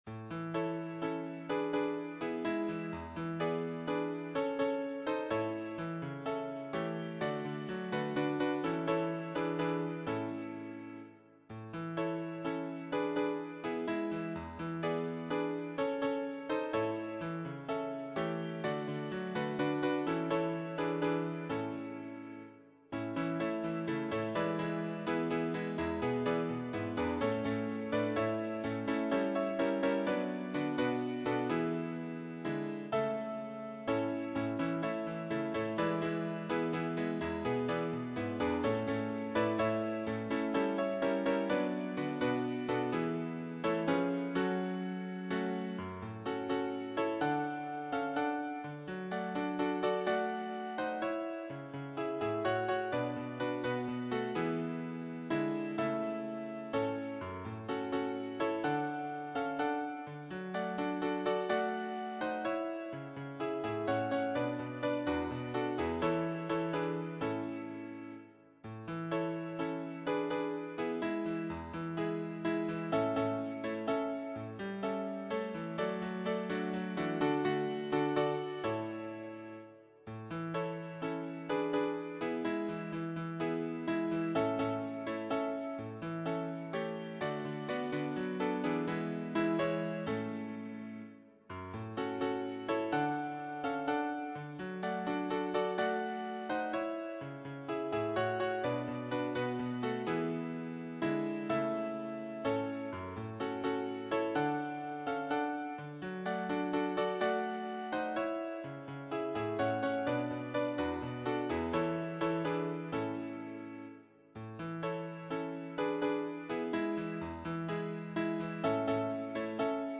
choir SAATB